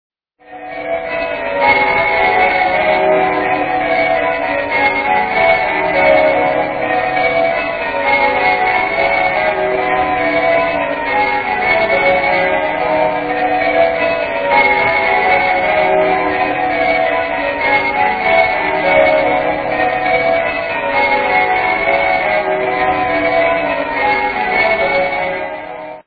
Bow Bells interval signal, gave place on August 11th to the present signal based on the
bbc bells.mp3